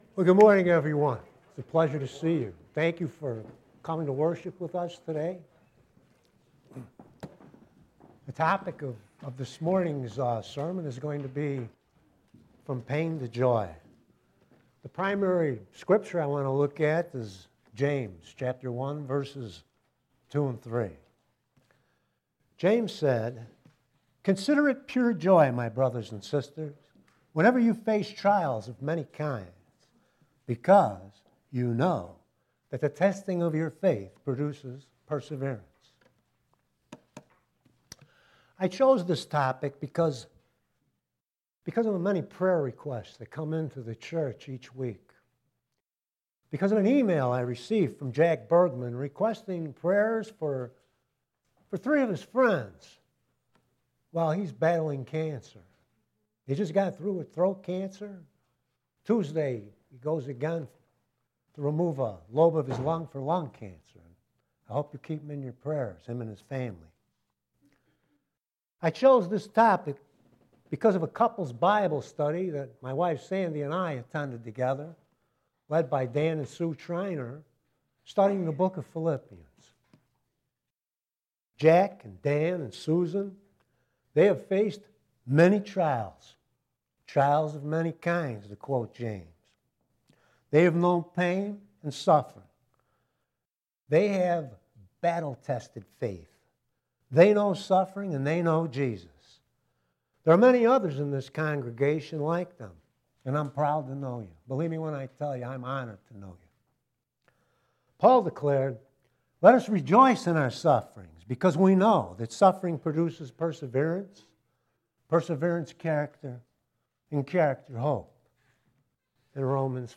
Note that there is only audio available for this sermon.